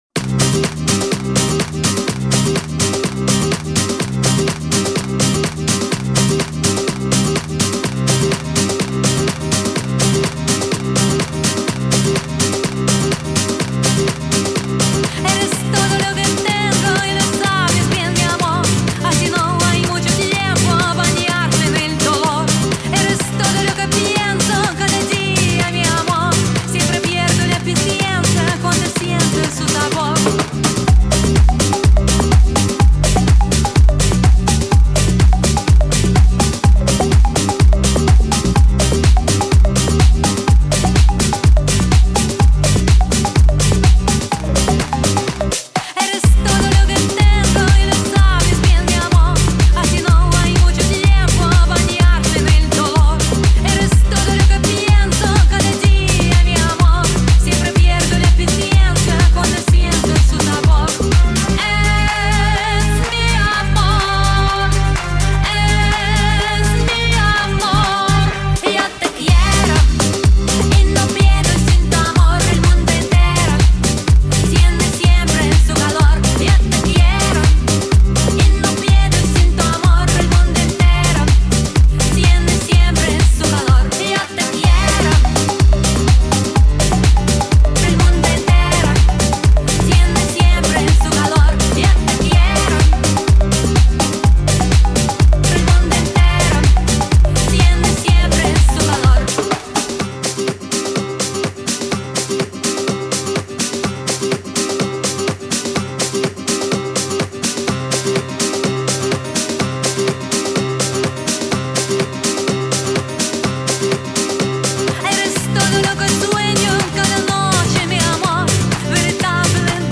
Жанр: Pop, Electronic, House
Стиль: House